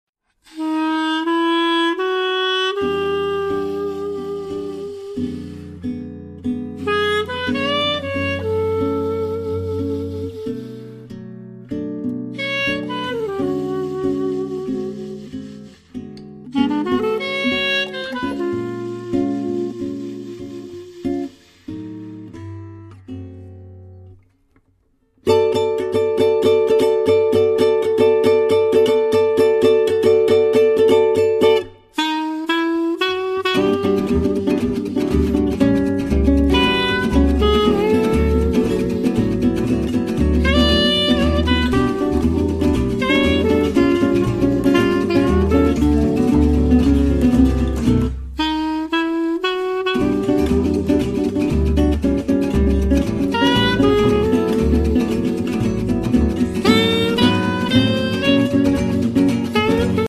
Clarinets
Guitar
Double Bass
è addirittura un samba